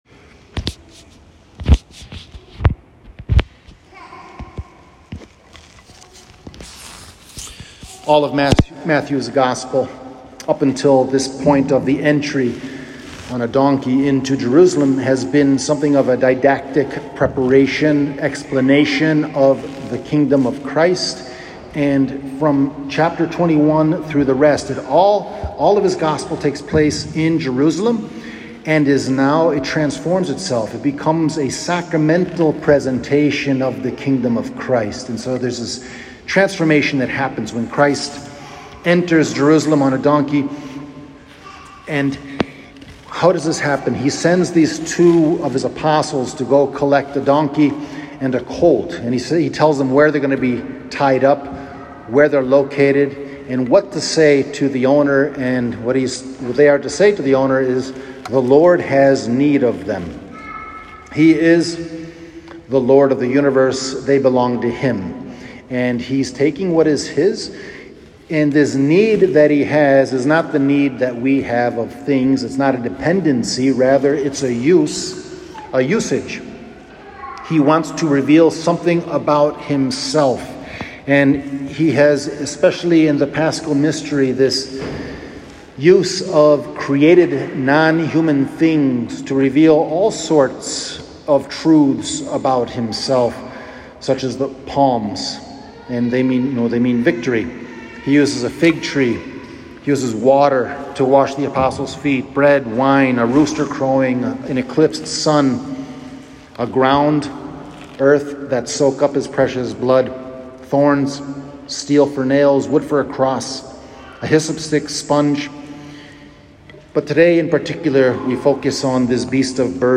Transcription of Homily